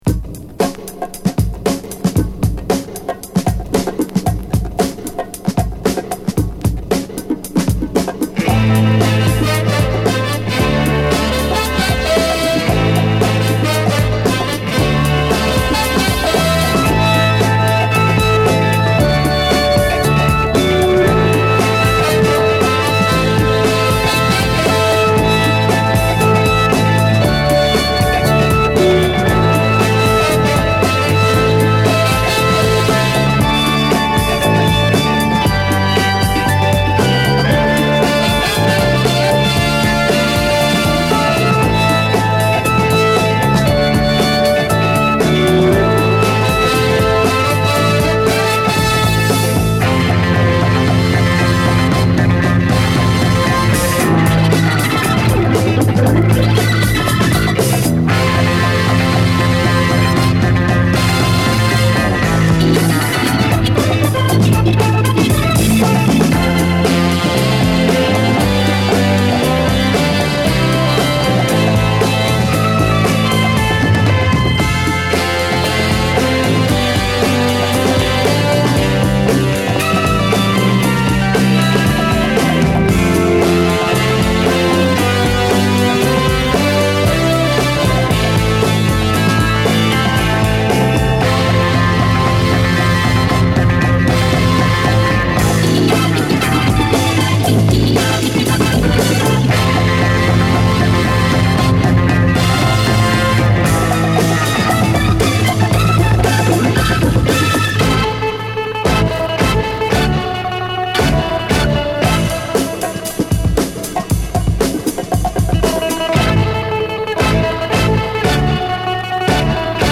Jamaica